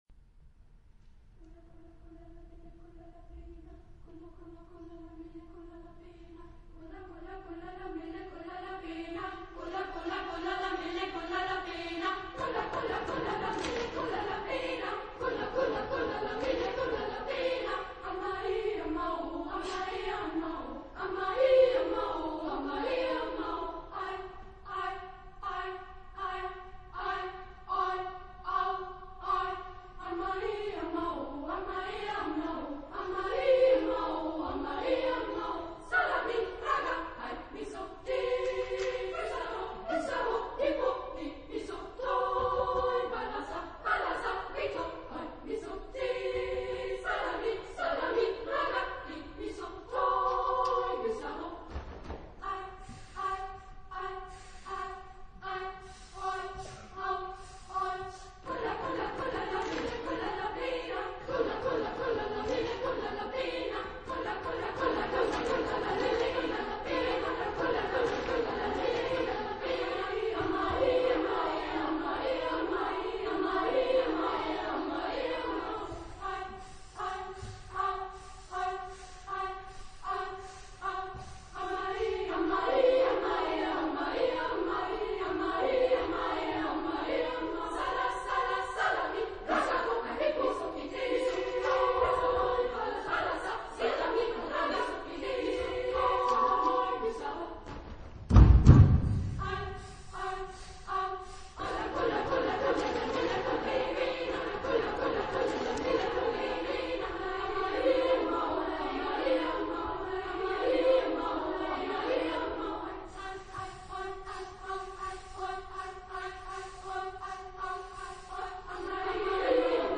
Chorgattung: SSAA  (4 Kinderchor Stimmen )
Tonart(en): ionisch
Aufnahme Bestellnummer: 7. Deutscher Chorwettbewerb 2006 Kiel
Lokalisierung : Voix Enfants Profane Acappella